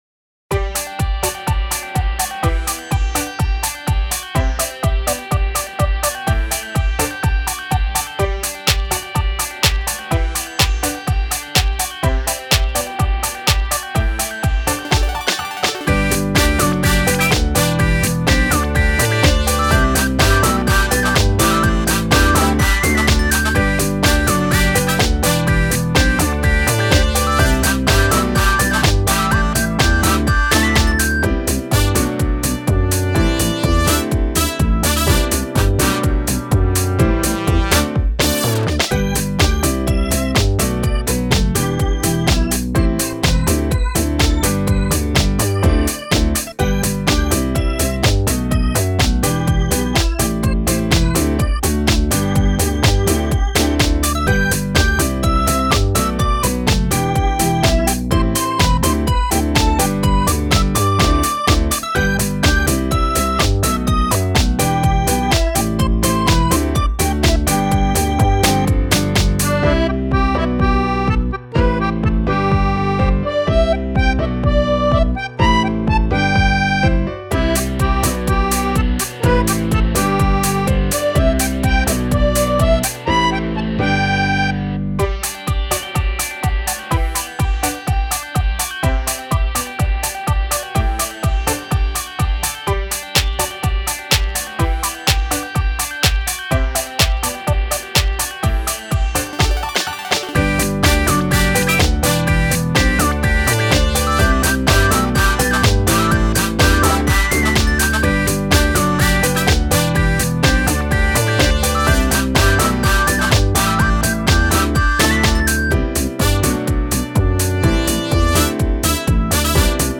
そんな何かが始まるイメージで作りました。